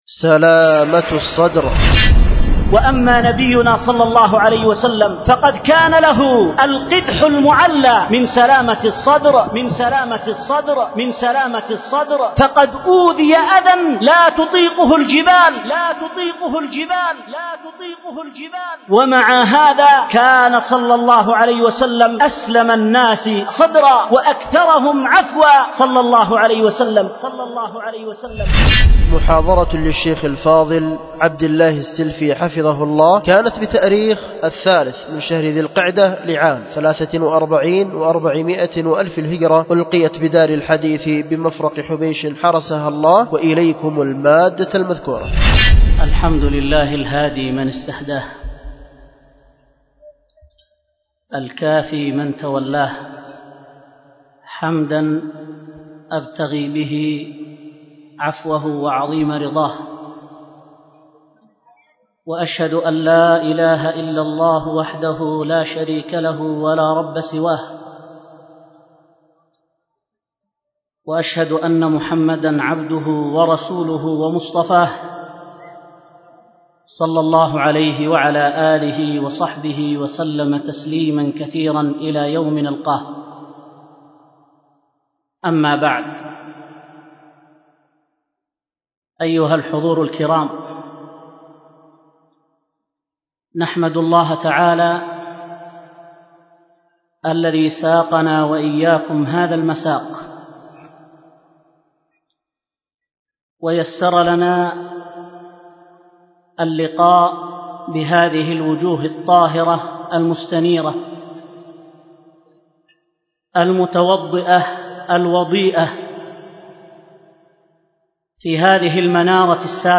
ألقيت بدار الحديث بمفرق حبيش